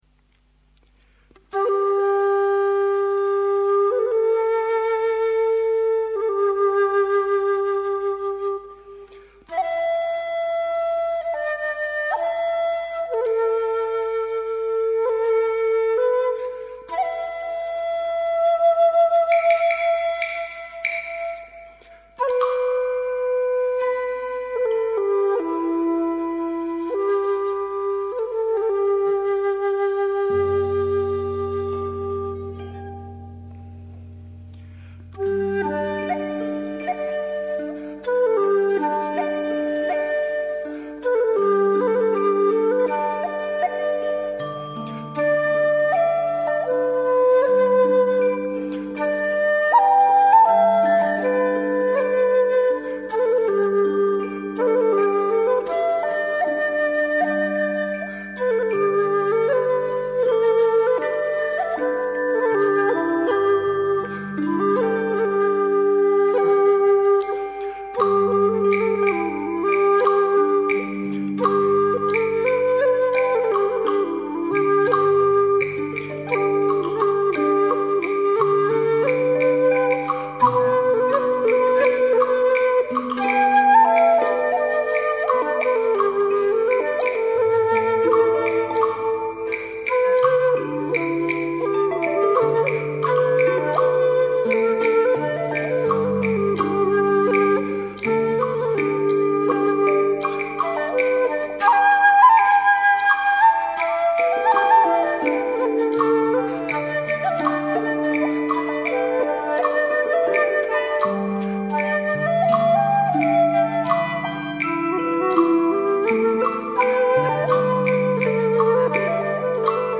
梅花三弄 >>>点击进入：梅花三弄 古琴曲《梅花三弄》，又名《梅花引》、《玉妃引》，是中国古典乐曲中表现梅花的佳作。全曲表现了梅花洁白，傲雪凌霜的高尚品性，是一首充满中国古代士大夫情趣的琴曲。